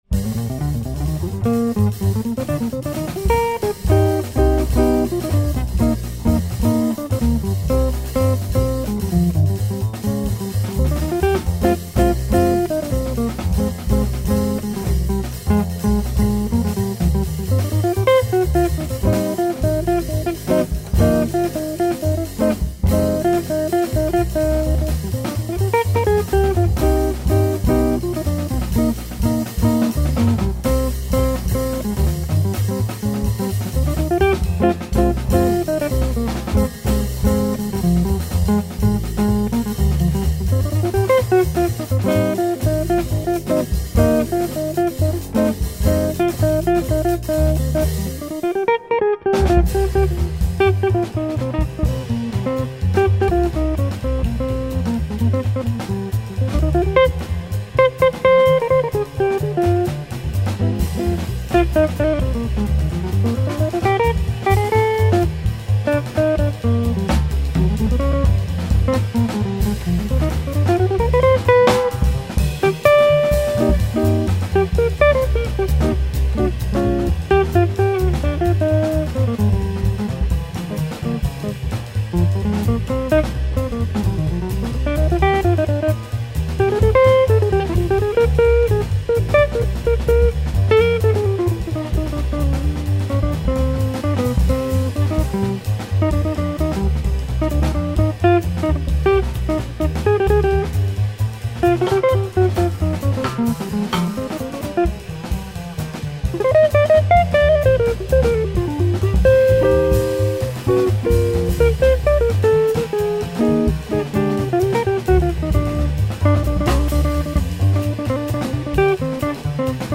Straight-ahead / Bop.
is a compelling bop guitarist now in his late fifties.
a tight, swinging tandem